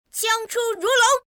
Game VO
Radiating chivalry and grace, she speaks with a cool, poised, and mature “older sister” vocal tone—elegantly conveying her composure, quiet authority, and innate dignity without raising her voice.